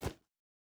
Jump Step Stone B.wav